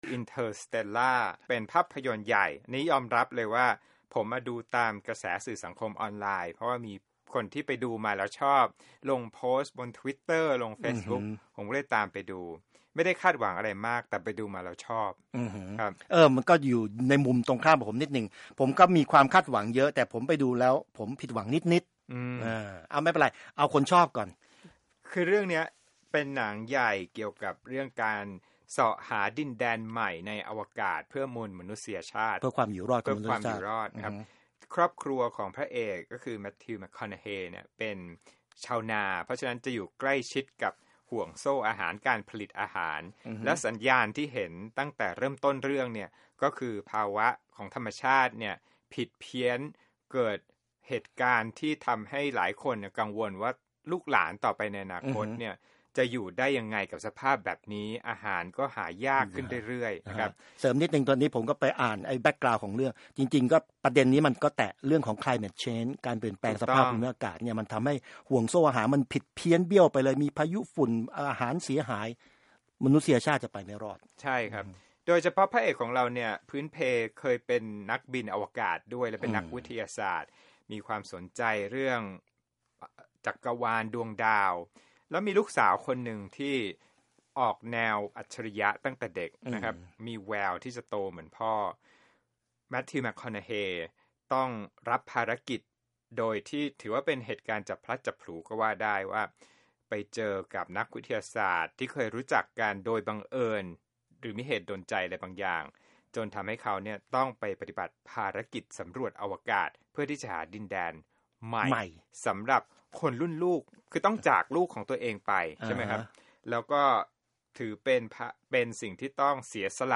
วิจารณ์ภาพยนตร์
Interstella Movie Review